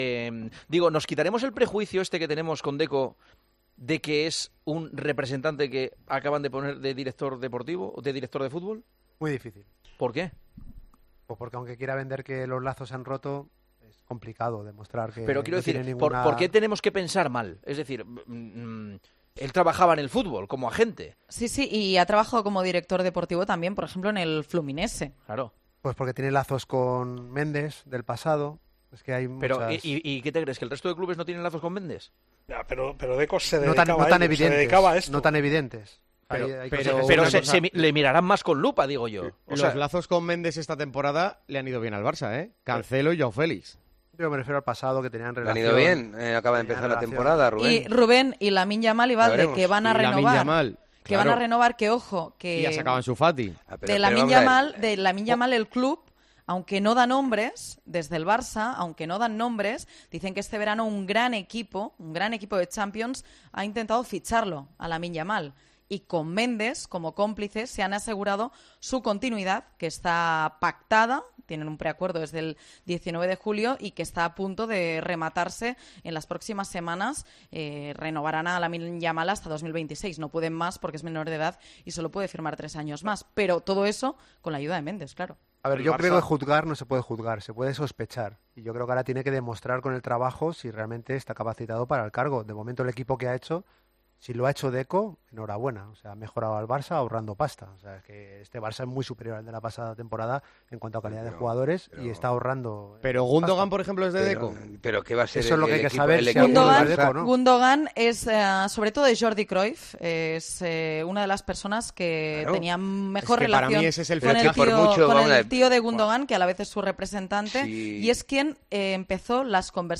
Juanma Castaño, presentador de El Partidazo de COPE, explicó un detalle que hace dudar de los fichajes que el nuevo director deportivo haga en el Barça: "Tenemos que ser justos".